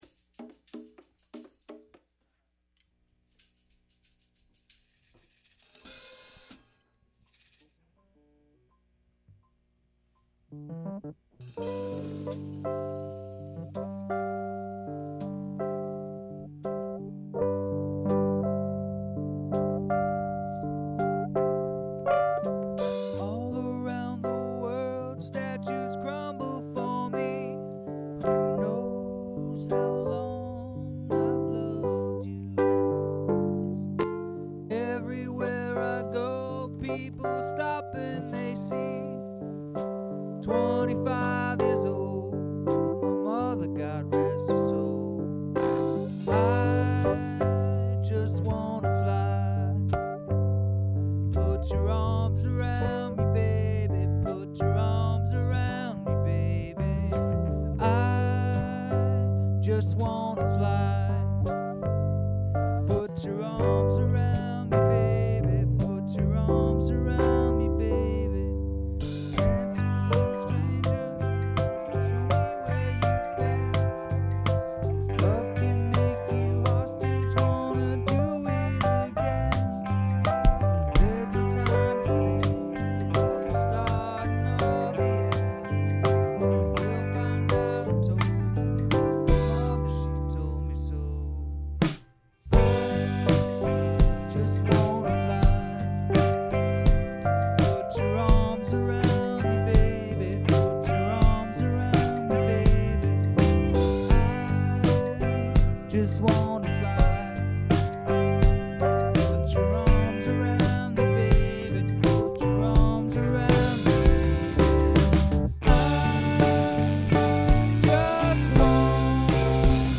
electric riff end on acoustic